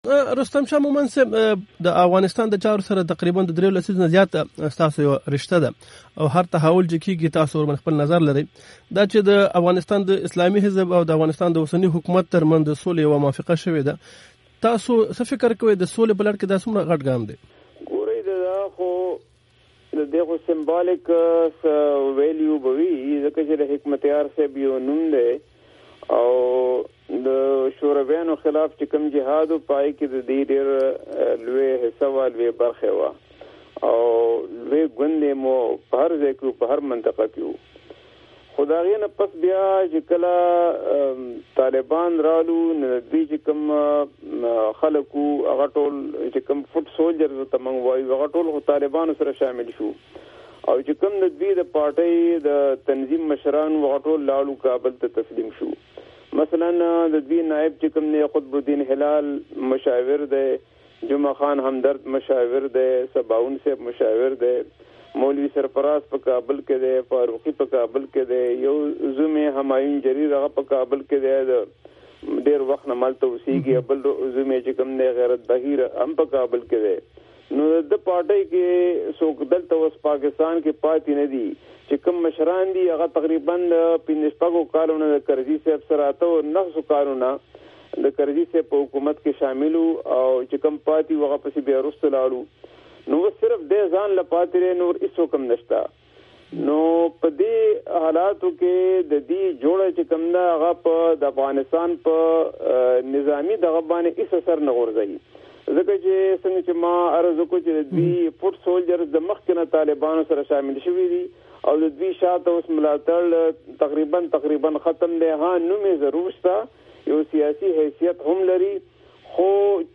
ښاغلي رستم شاه مومند دا خبرې د امریکا غږ اشنا راډیو سره یوه مرکه کې کړیدي
کابل کې د پاکستان پخواني سفیر ښاغلي رستم شاه مومند سره مرکه